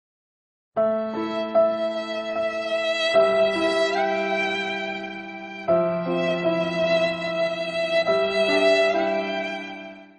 Sad Violine
Sad-Violine.mp3